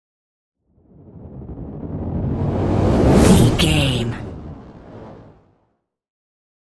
Whoosh to hit fire
Sound Effects
dark
intense
tension
woosh to hit